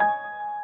piano67.ogg